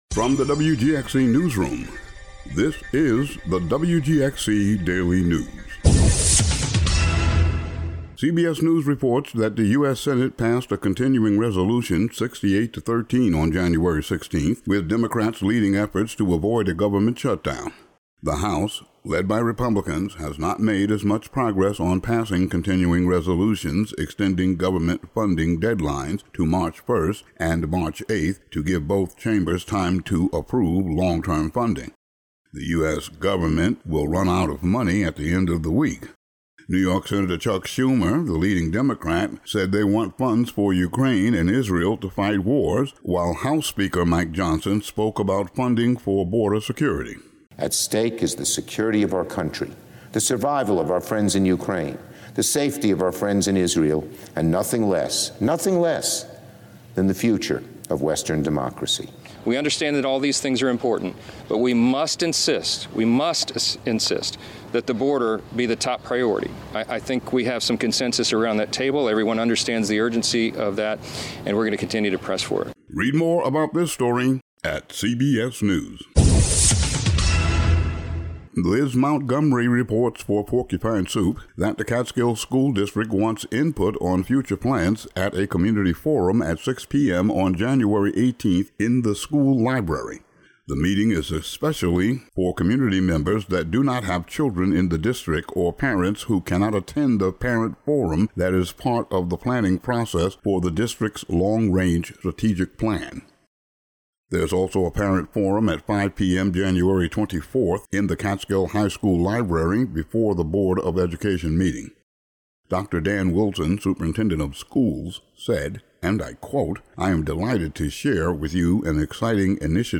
Today's audio daily news update.